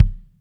CM KICK 28.wav